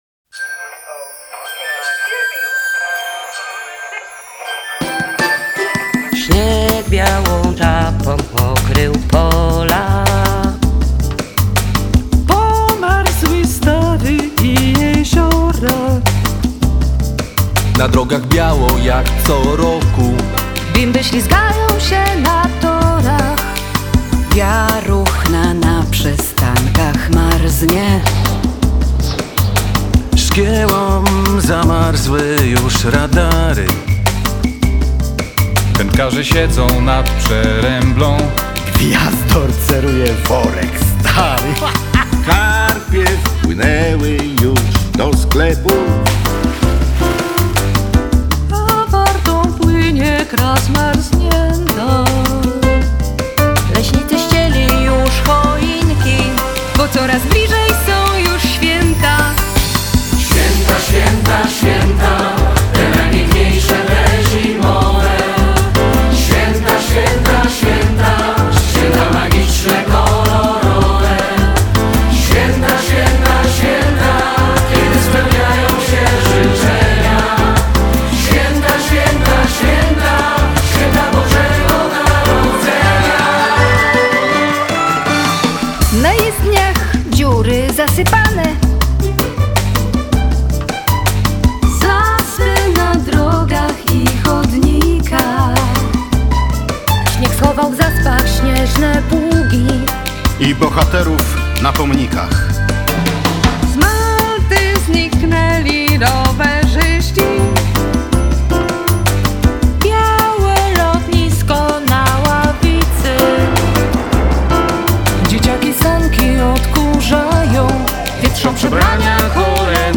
Dziennikarze Radia Merkury i TVP Poznań nagrywają specjalną piosenkę świąteczną.